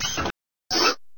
|align=center| A tricked-out propellor cap's hydraulics
|align=center| Strong Bad's [[Grabbo Arm]]